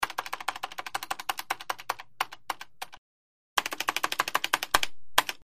Mac Keyboard 1; Desktop Keyboard; Tap Delete Key, Then Spacebar, Close Perspective.